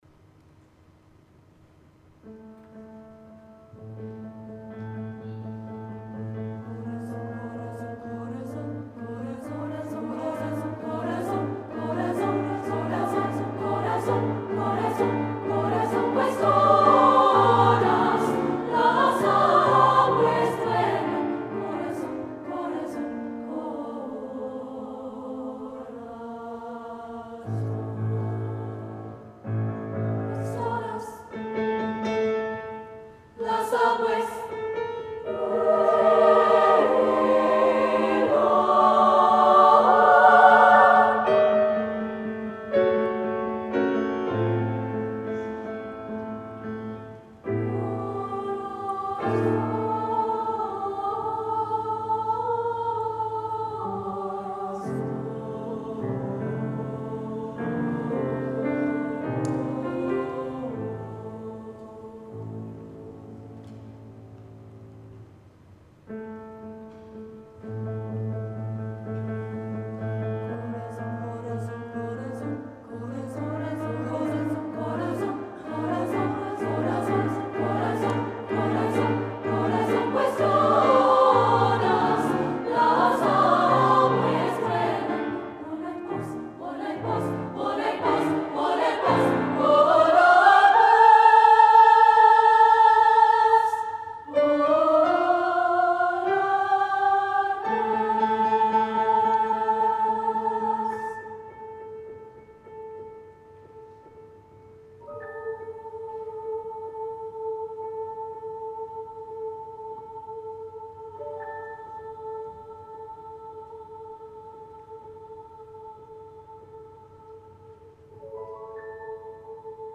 Recordings from The Big Sing National Final.
Bella Voce Marlborough Girls' College Pues Todas Las Aves Vuelan Loading the player ...